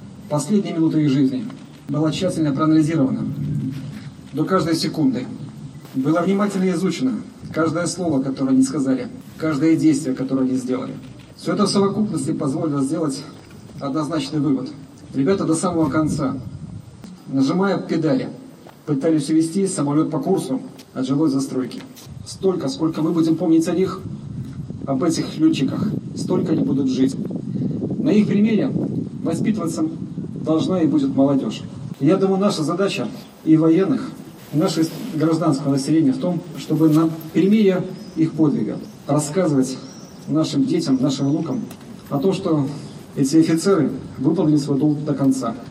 В Барановичах прошел митинг в память о погибших летчиках